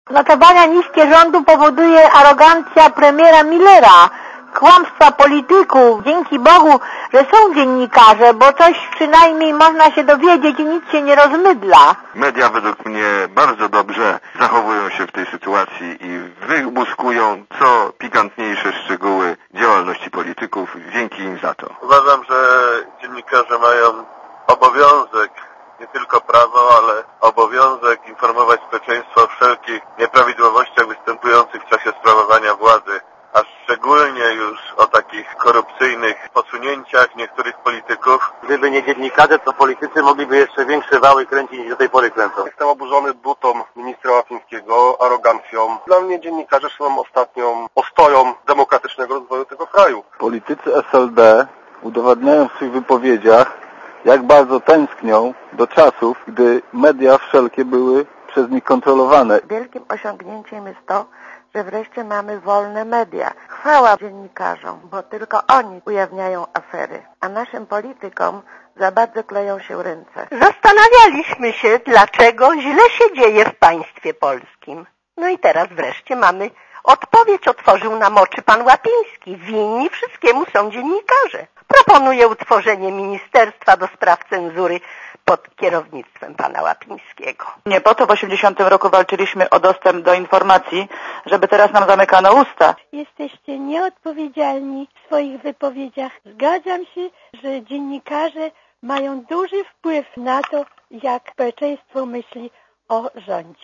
Co sądzą o tym słuchacze Radia Zet?